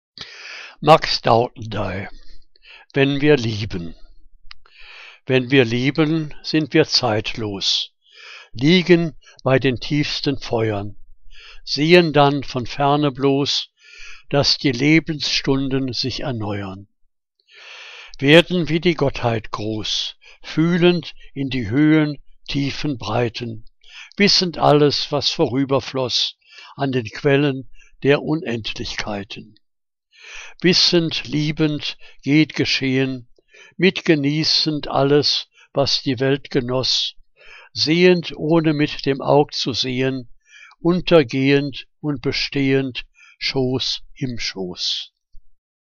Liebeslyrik deutscher Dichter und Dichterinnen - gesprochen (Max Dauthendey)